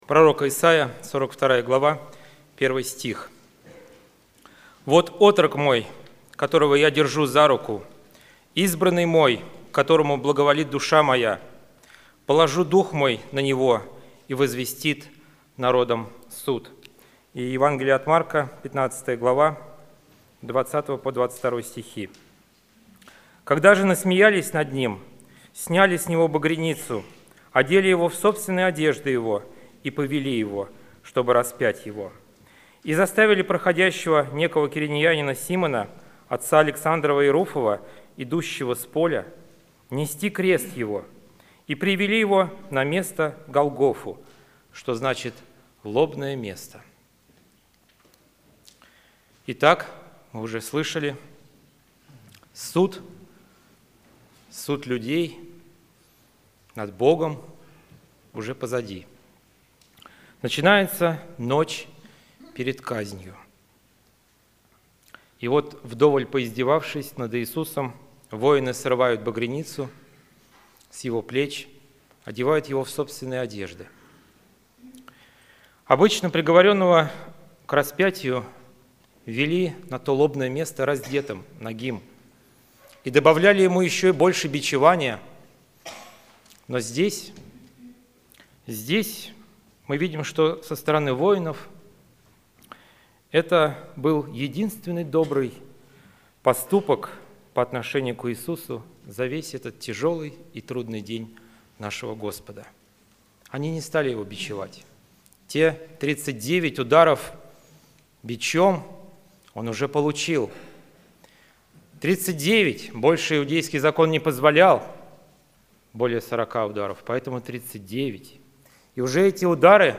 Церковь: Московская Центральная Церковь ЕХБ (Местная религиозная организация "Церковь евангельских христиан-баптистов г. Москвы")